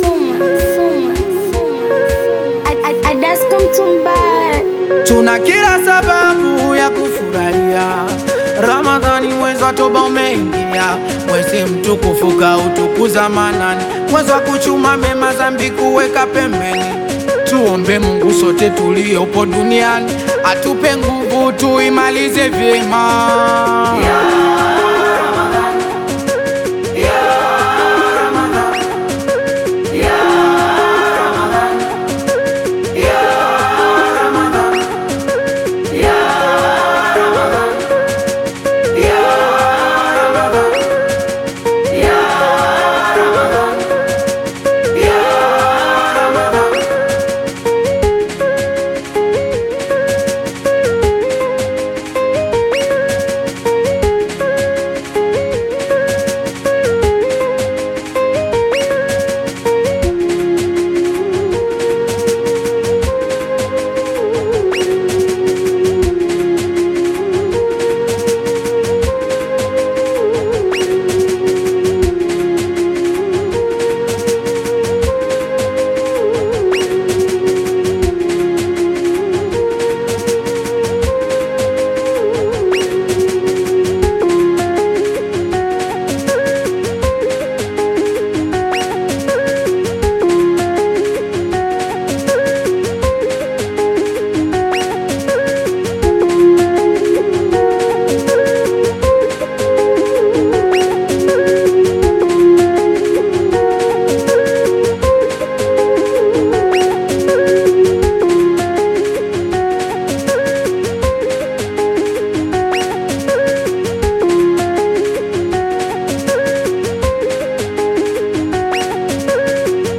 AudioQaswida
Tanzanian Singeli single